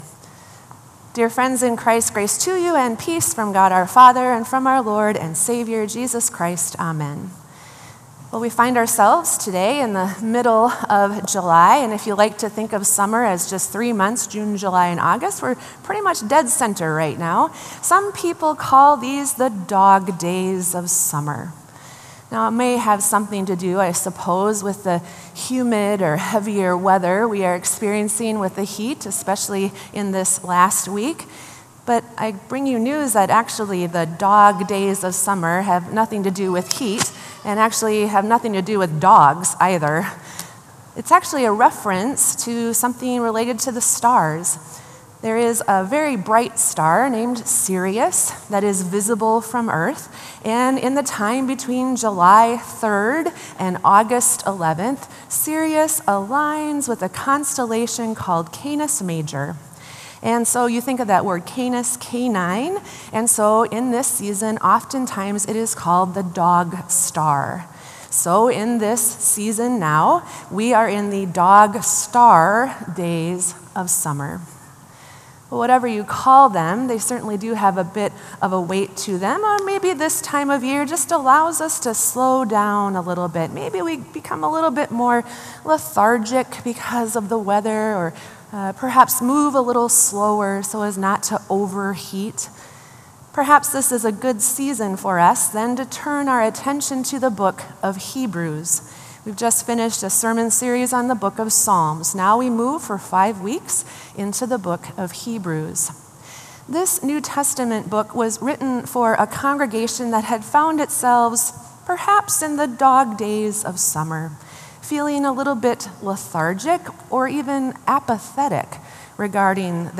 Sermon “Listening For the Word”